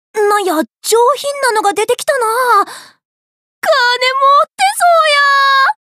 ボイスセレクションと川神通信Ｑ＆Ａです